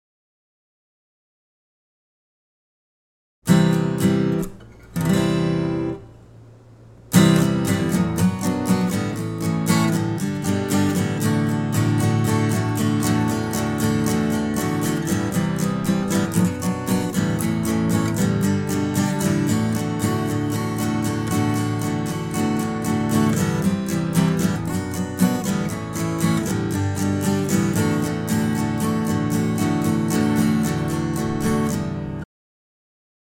Help with mic'ing/recording acoustic guitar
Still, they sounded a little muffled on playback. On playback, I added Reaper's JS: Presence EQ at these settings: That got rid of the muddiness without making it too bright - actually gave it a slightly smoother sound.
In the .mp3 I've attached, there is some room noise at the beginning. That's because I didn't bother to shut off the A/C. When I record for real that won't be there.